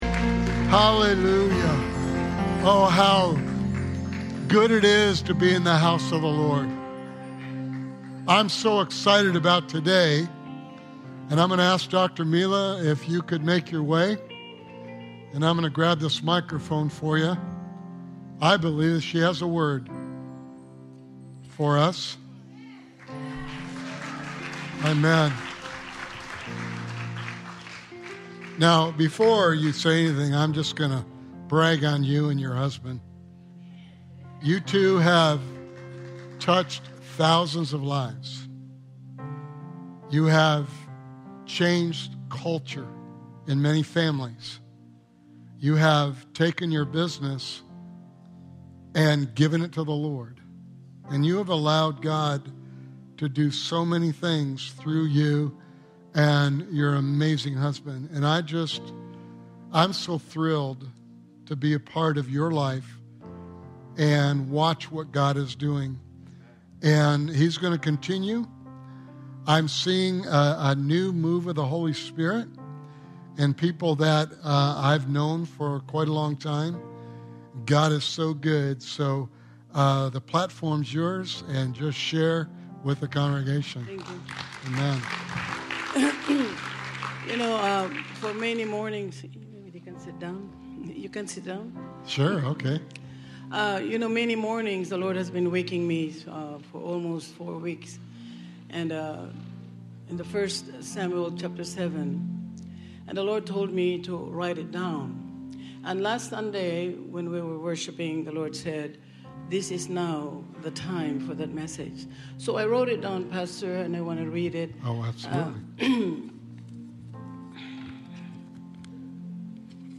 Sermon Series: I Am Free